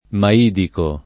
[ ma & diko ]